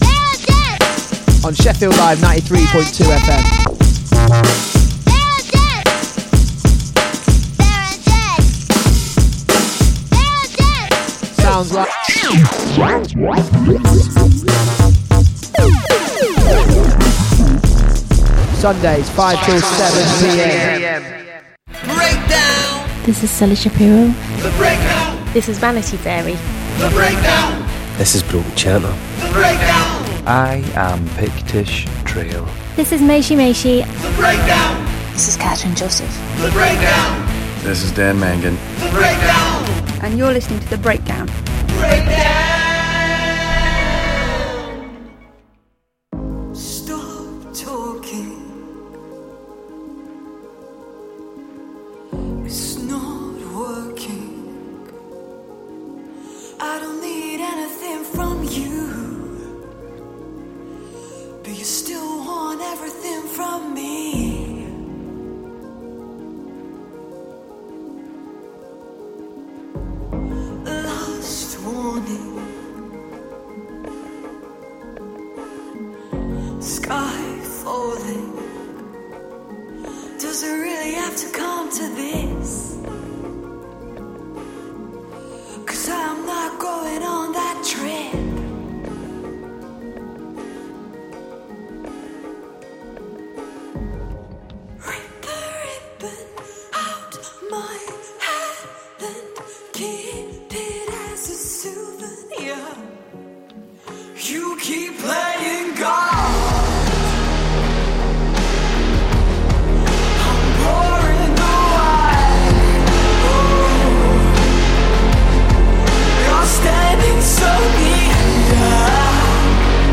A weekly musical jamboree transmitting live to the world every Saturday afternoon.